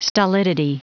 Prononciation du mot stolidity en anglais (fichier audio)
Prononciation du mot : stolidity